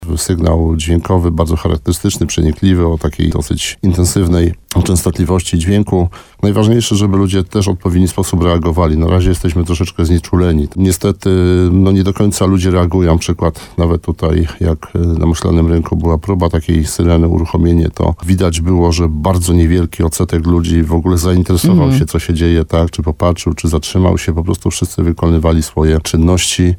Komendant Państwowej Straży Pożarnej w Nowym Sączu Sławomir Wojta mówił w programie Słowo za Słowo na antenie RDN Nowy Sącz, że nowe dźwięki różnią się od dawniej stosowanych sygnałów.
Rozmowa z komendantem Państwowej Straży Pożarnej w Nowym Sączu: Tagi: alarm syrena system ostrzegania Nowy Sącz Słowo za Słowo straż pożarna Państwowa Straż Pożarna PSP HOT